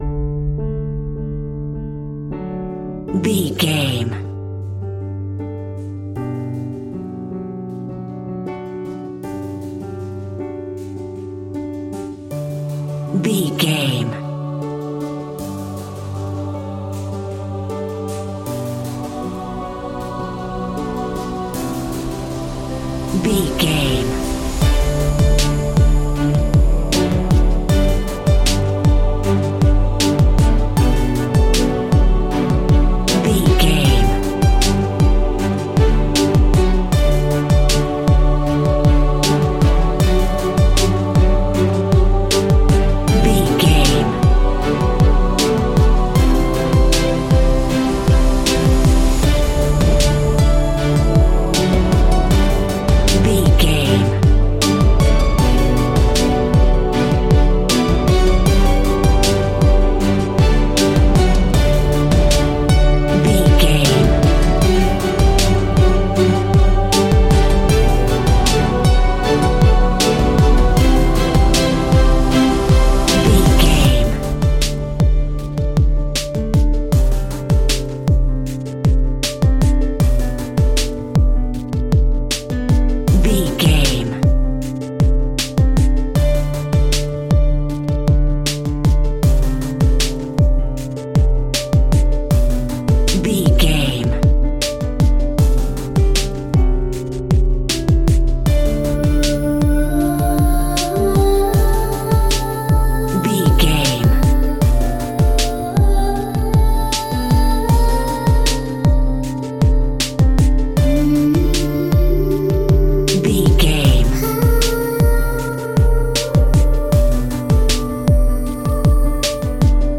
Ionian/Major
D
dramatic
epic
strings
percussion
synthesiser
brass
violin
cello
double bass